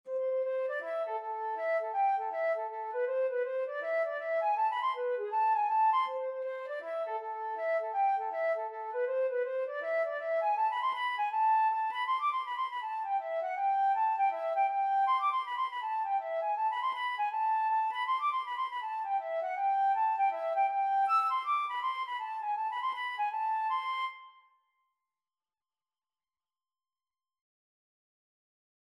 Flute version
4/4 (View more 4/4 Music)
A minor (Sounding Pitch) (View more A minor Music for Flute )
Flute  (View more Easy Flute Music)
Traditional (View more Traditional Flute Music)
world (View more world Flute Music)